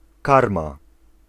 Ääntäminen
Synonyymit pasza karman Ääntäminen Tuntematon aksentti: IPA: [ˈkarma] Haettu sana löytyi näillä lähdekielillä: puola Käännös 1. fourrage {m} Suku: f .